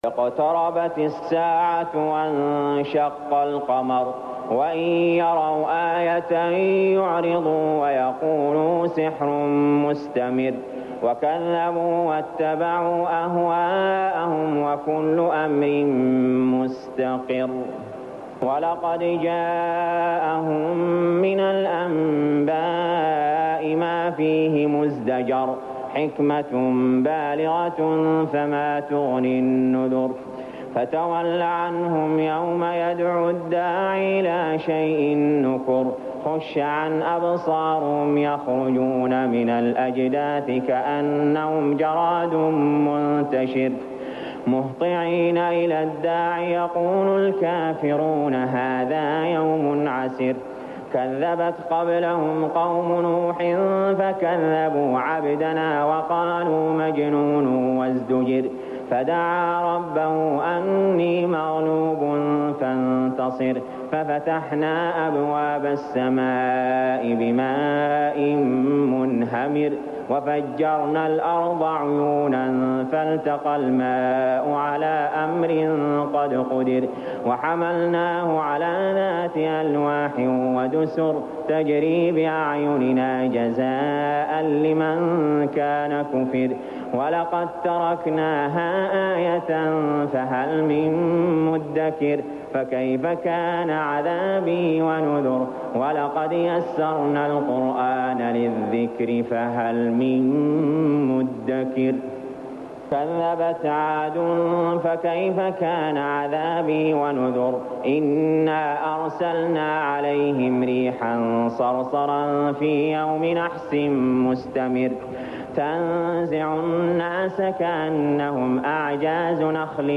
المكان: المسجد الحرام الشيخ: علي جابر رحمه الله علي جابر رحمه الله القمر